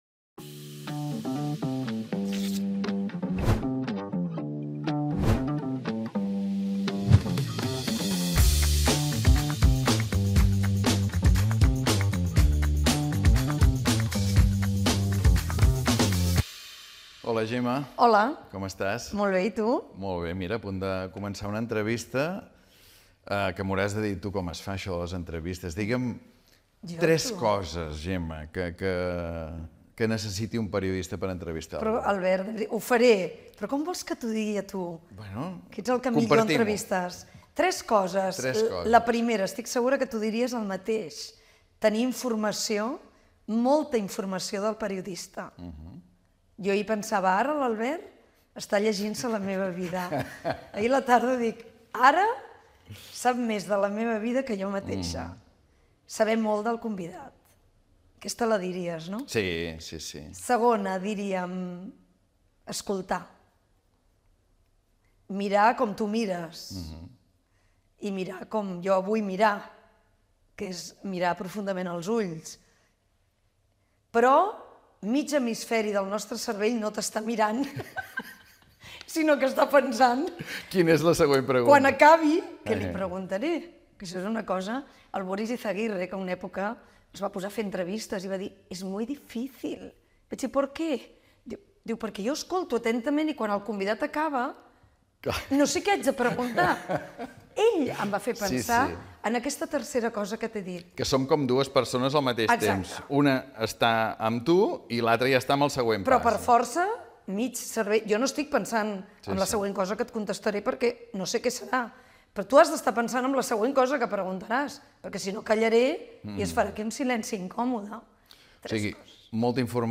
Periodistes. Converses sobre l’ofici Descripció Sintonia i entrevista, feta a la seu de Barcelona del Col·legi de Periodistes de Catalunya, a la periodista Gemma Nierga. S'hi parla de la forma com s'han de fer les entrevistes i el to davant del micròfon
Pòdcast produït pel Col·legi de Periodistes de Catalunya, presentat per Albert Om.